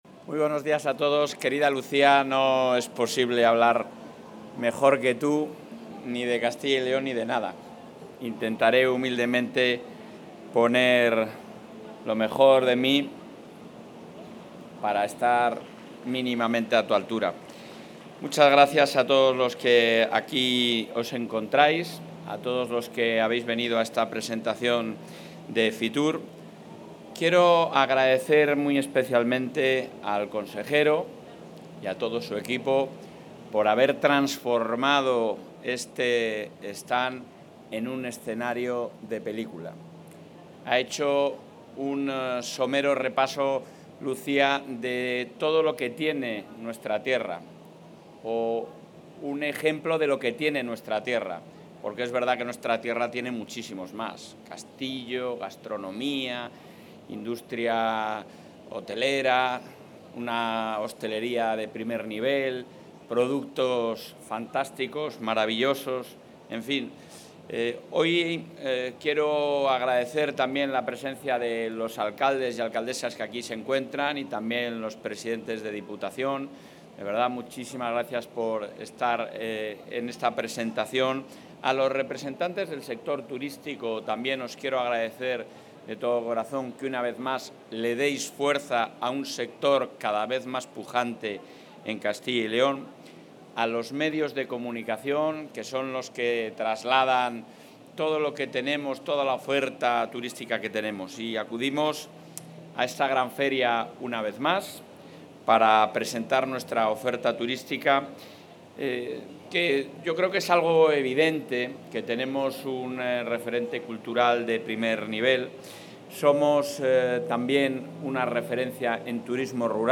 Intervención del presidente de la Junta.
El presidente del Gobierno autonómico, Alfonso Fernández Mañueco, ha visitado hoy la 45ª edición de la Feria Internacional de Turismo, FITUR 2025, en la que la Comunidad se ha presentado con el lema 'Castilla y León, escenario de película'.